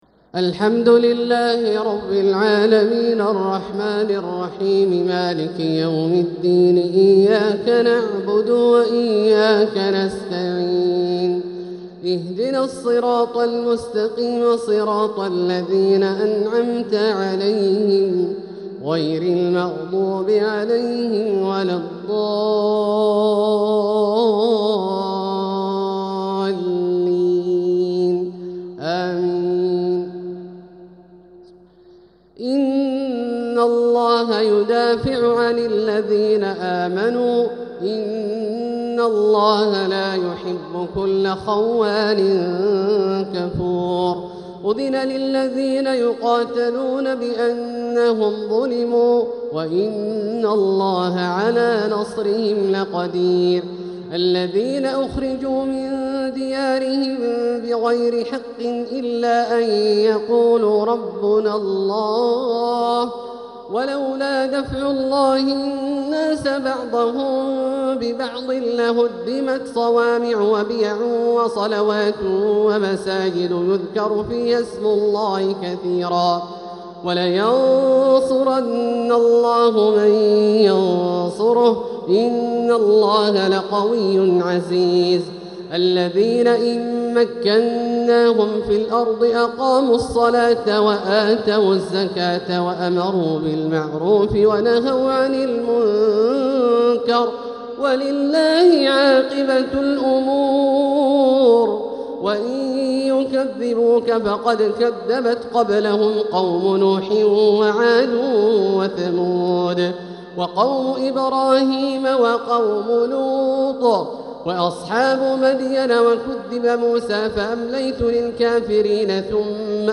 تراويح ليلة 22 رمضان 1446هـ من سورتي الحج (38-78) و المؤمنون (1-50) | Taraweeh 22nd night Ramadan 1446H Surah Al-Hajj and Al-Muminoon > تراويح الحرم المكي عام 1446 🕋 > التراويح - تلاوات الحرمين